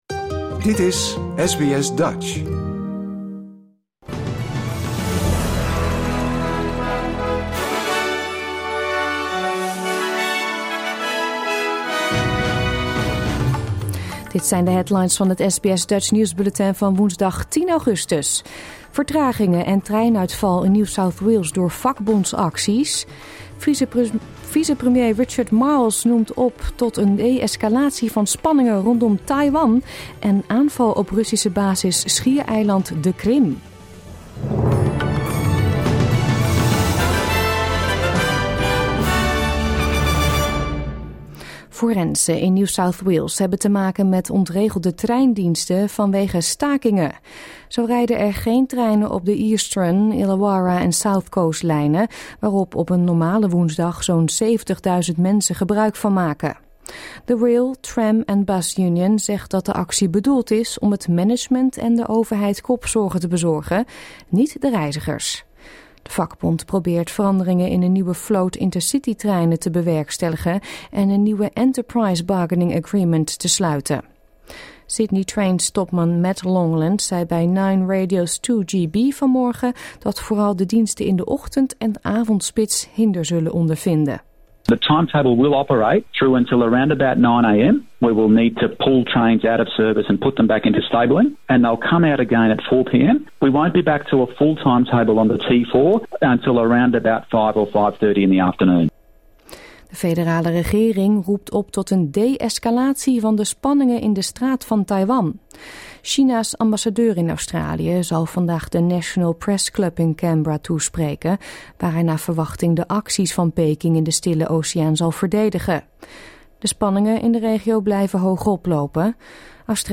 Nederlands / Australisch SBS Dutch nieuwsbulletin van woensdag 10 augustus 2022